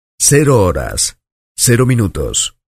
Grabación sonora de locutor retransmitiendo el siguiente comentario: "cero horas, cero minutos"